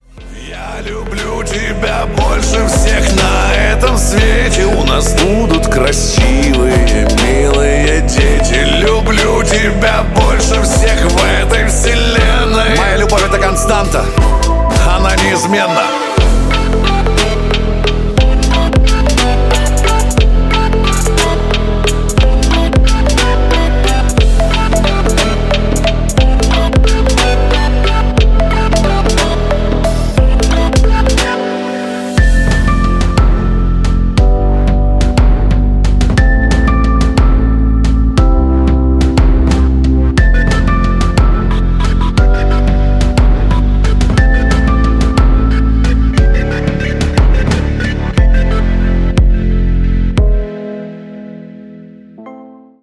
• Качество: 128, Stereo
поп
Electronic